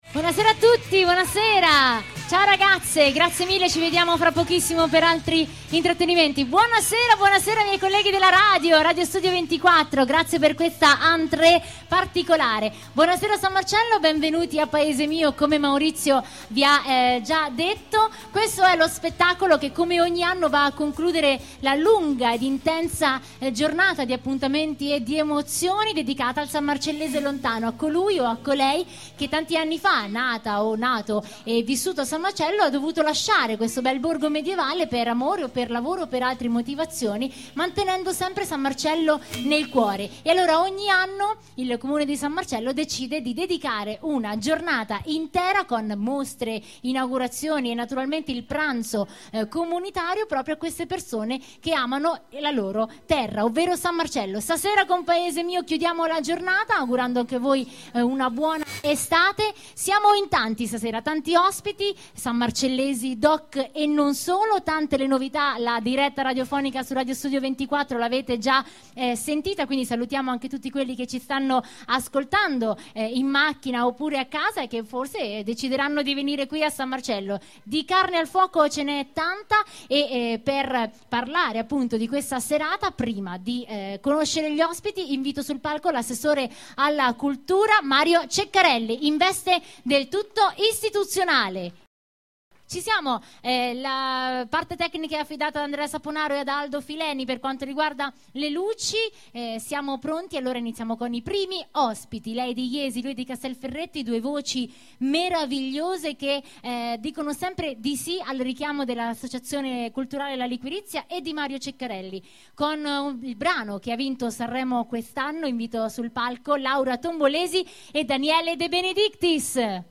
Sprecherin italienisch. 28 anni.
Sprechprobe: eLearning (Muttersprache):
Italian female voice over artist. 28 anni.